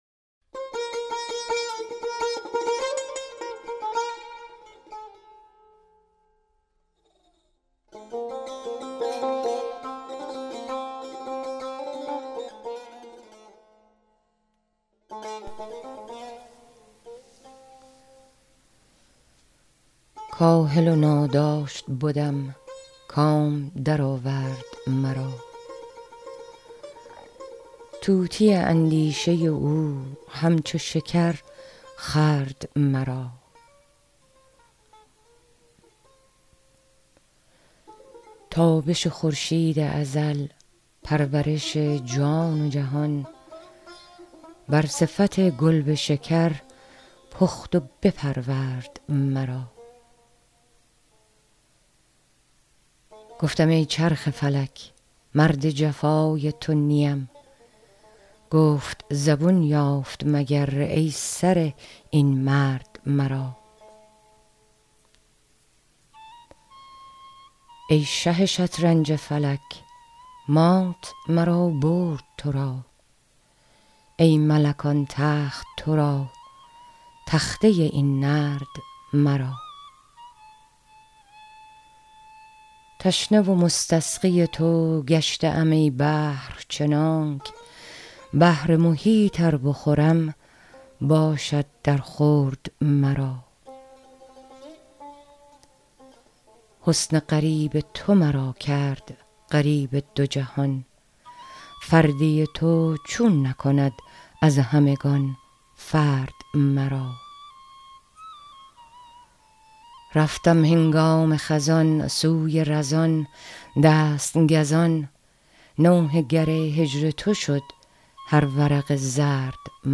مولانا دیوان شمس » غزلیات غزل شمارهٔ ۴۳ به خوانش